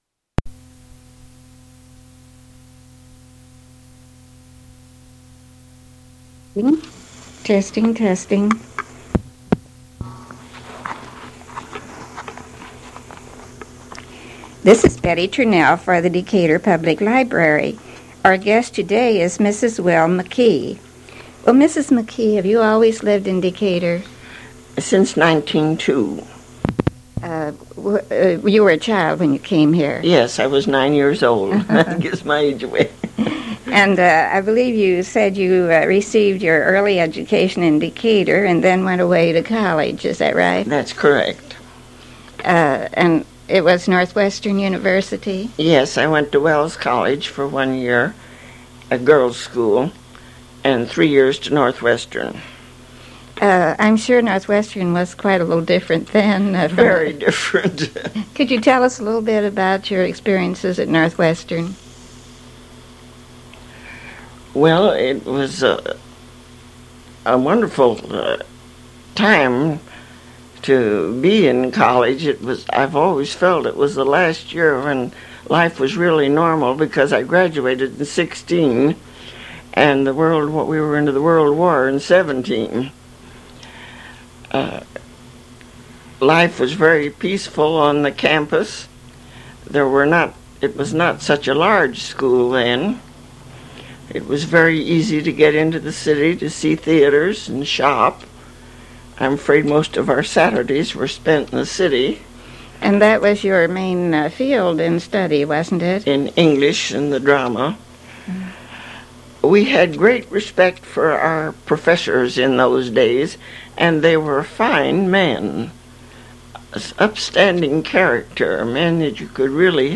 cassette tape
oral history